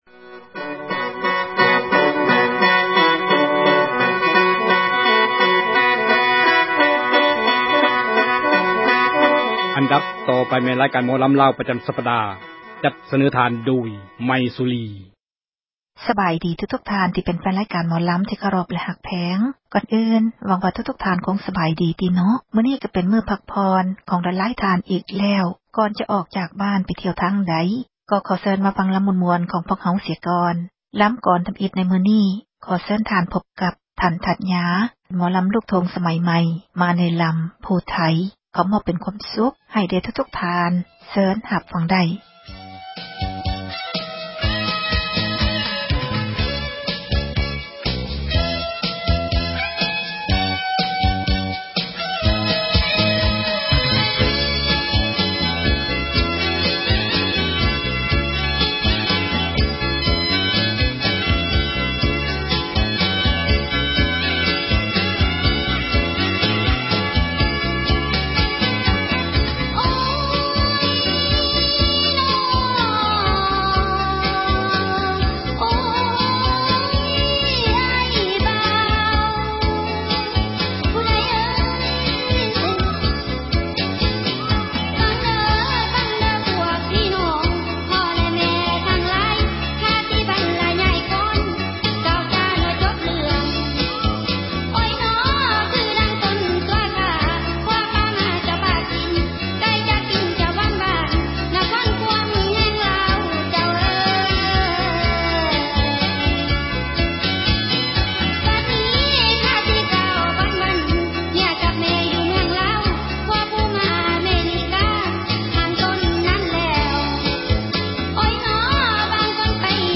ອັນດັບຕໍ່ໄປແມ່ນ ຣາຍການໝໍລຳລາວ ປະຈຳສັປດາ ຈັດສເນີທ່ານ ໂດຍ